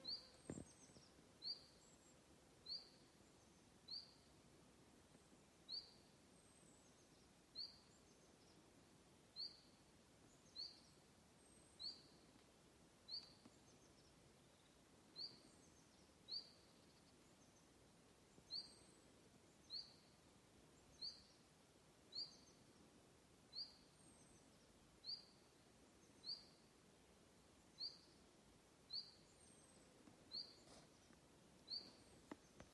Putni -> Ķauķi ->
Čuņčiņš, Phylloscopus collybita
StatussDzirdēta balss, saucieni
PiezīmesDzirdēts purva malā